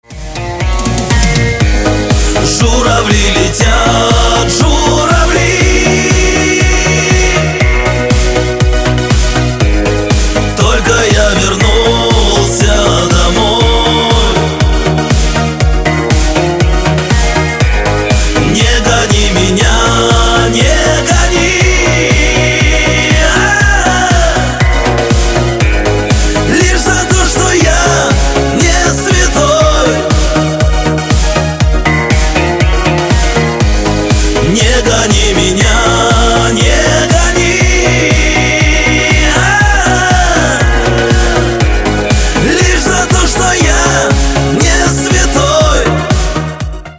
Рингтоны 2024 из жанра шансон
• Песня: Рингтон, нарезка